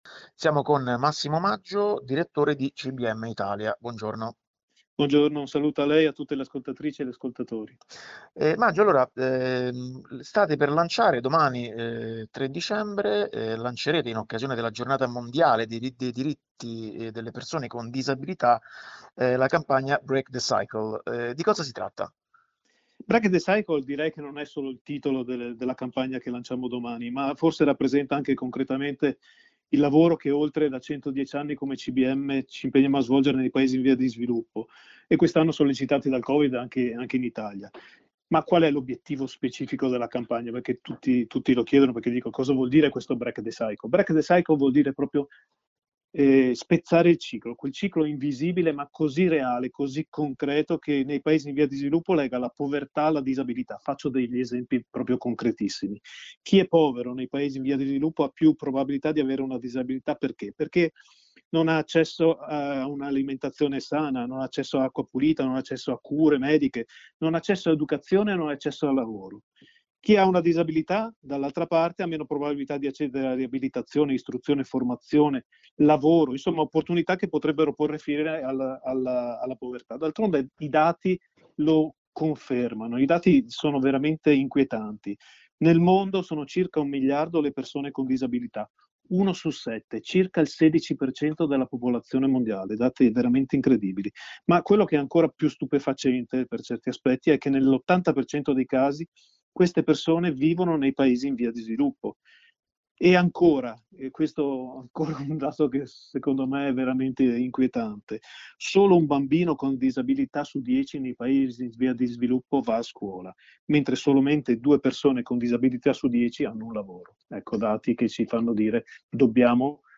Archivi categoria: Intervista